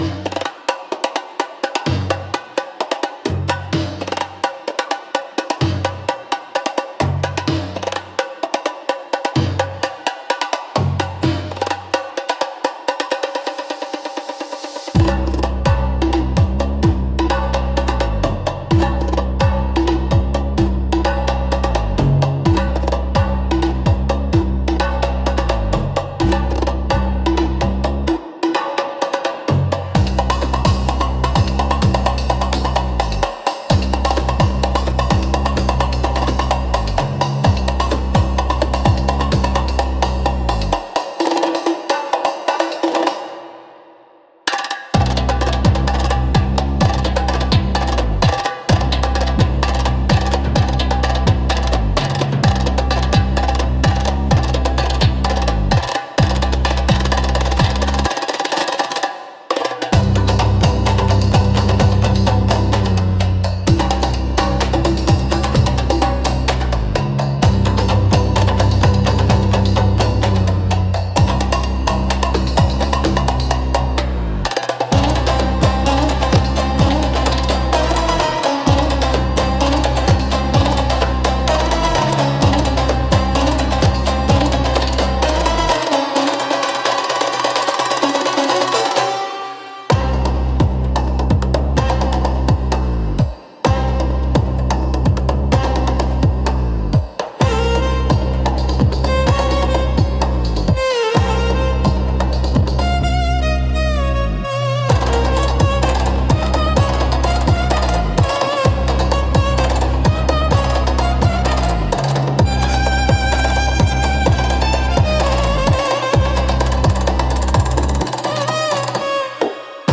Instrumental - Snake Dance Ritual